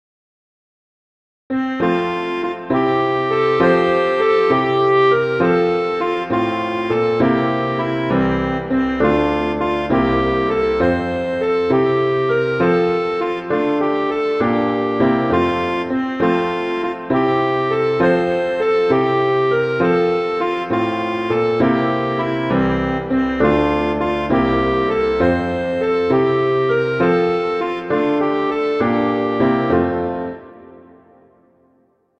clarinet and piano